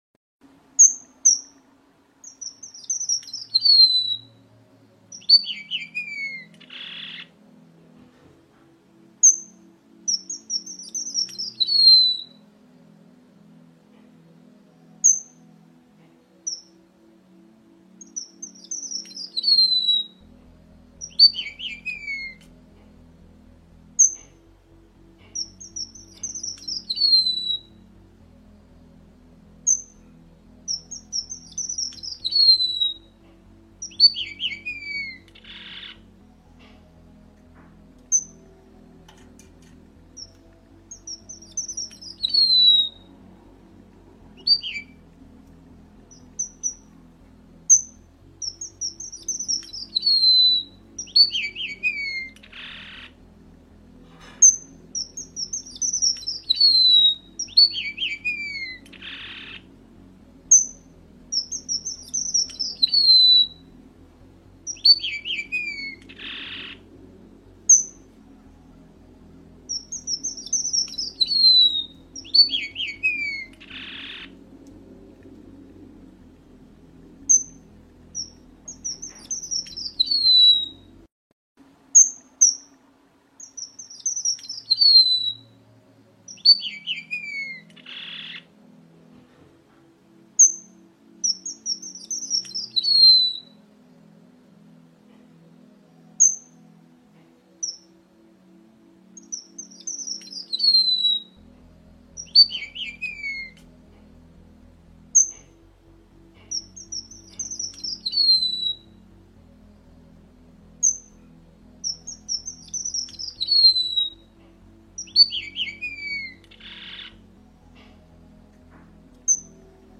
Canto do vermelho bengali
canto-do-vermelho-bengali.mp3